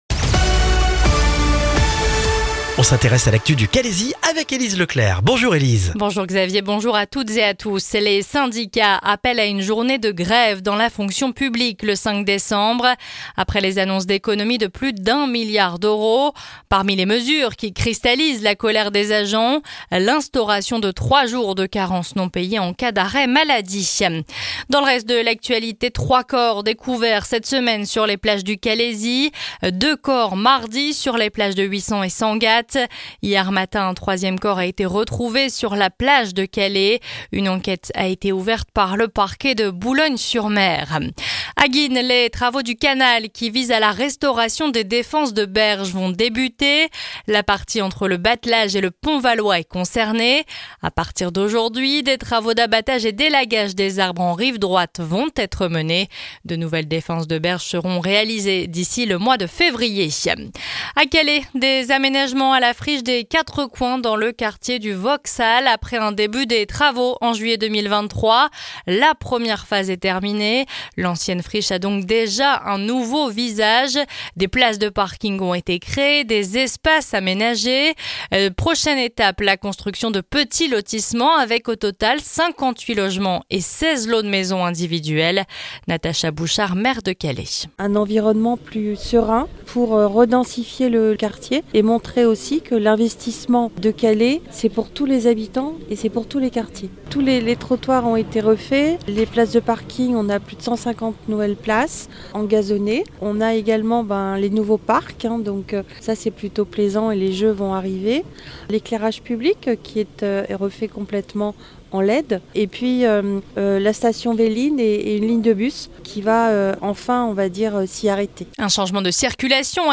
Le journal du vendredi 15 novembre dans le Calaisis